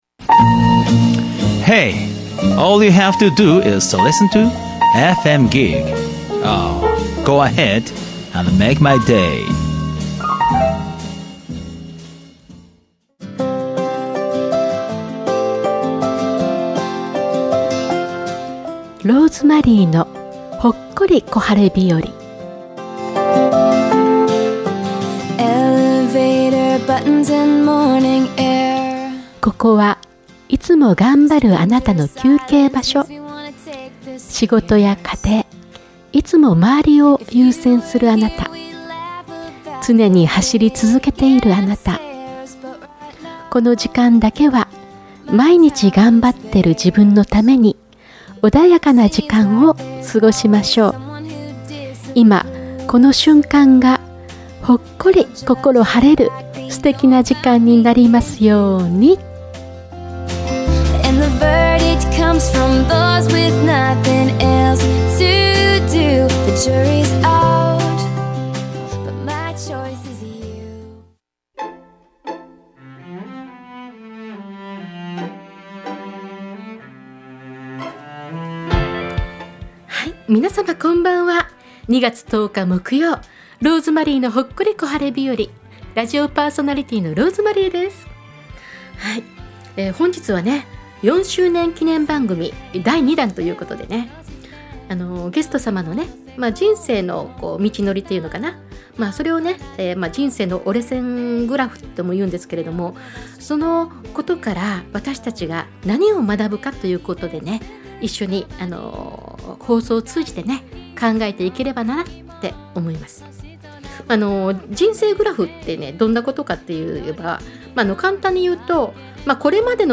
４周年記念放送にゲストとして２週連続出演させていただきました。 幼少期、母親から受けた育児放棄（ネグレクト）虐待からはじまる人生をどのように生きて、そしてその過酷でネガティブな出来事は成長する時間とともに、どのように受け止めどのような意味があると感じアイデンティティを確立していったのでしょうか。